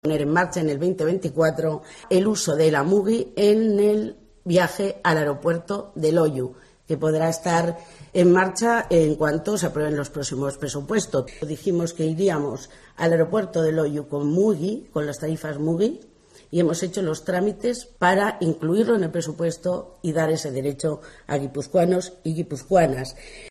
Rafaela Romero, diputada de Movilidad de Gipuzkoa